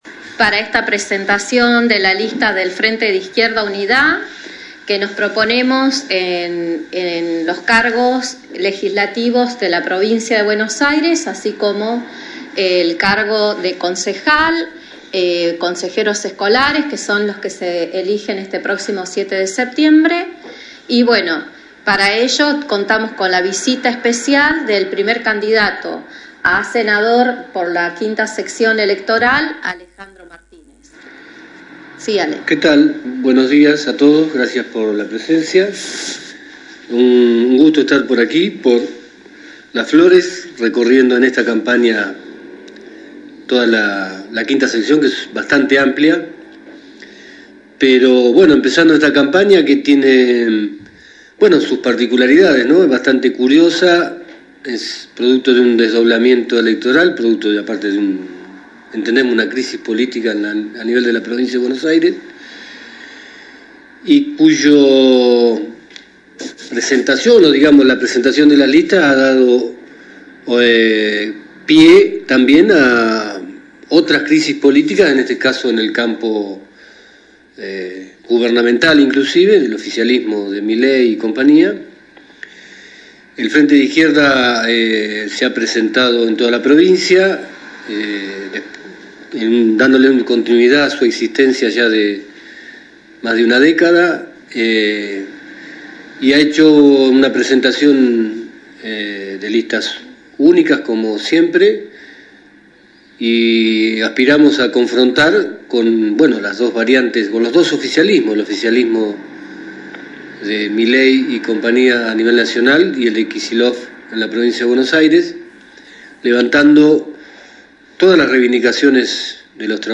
La conferencia de prensa y posterior charla abierta tuvo lugar en el local de Avda. San Martín 225.